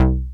ARP BASS 6.wav